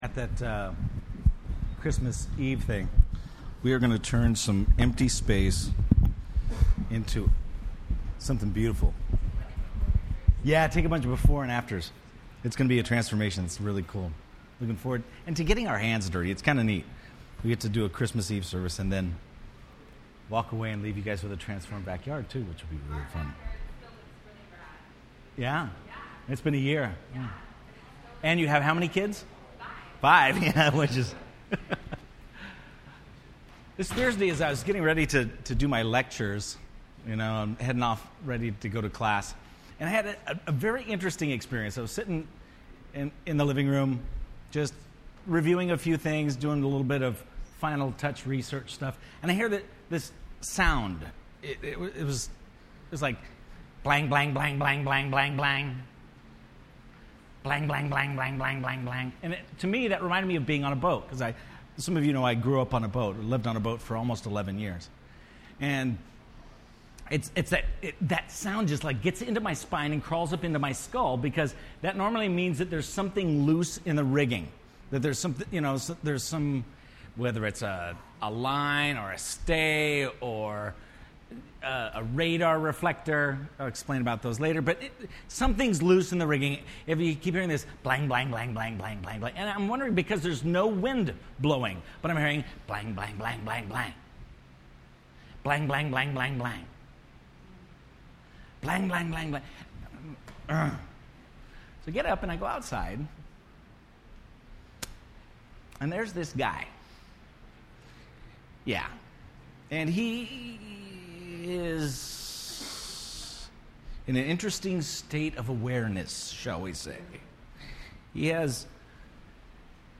The Core Service Type: Sunday Evening %todo_render% Related « The Core